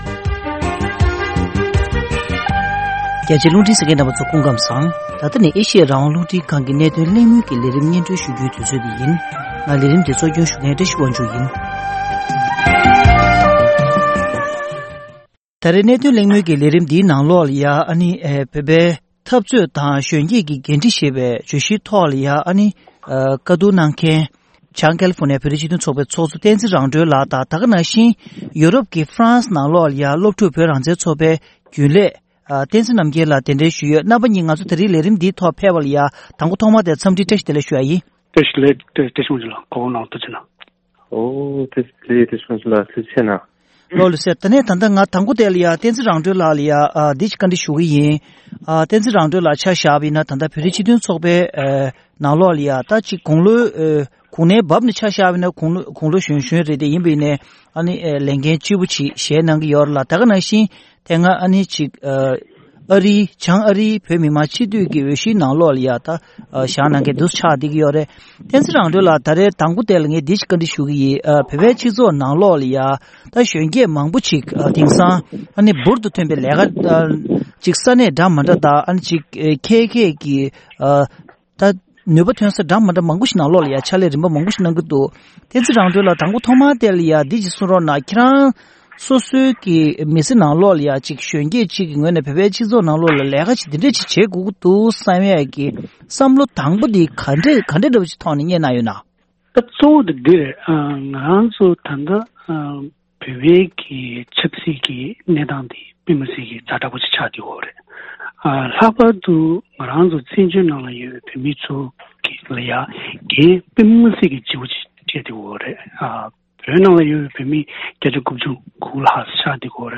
བོད་ཀྱི་འཐབ་རྩོད་དང་གཞོན་སྐྱེས་ཀྱི་འགན་འཁྲི་ཞེས་པའི་བརྗོད་གཞིའི་ཐོག་གླེང་མོལ།